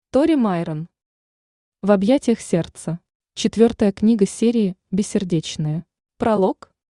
Аудиокнига В объятиях сердца | Библиотека аудиокниг
Aудиокнига В объятиях сердца Автор Тори Майрон Читает аудиокнигу Авточтец ЛитРес.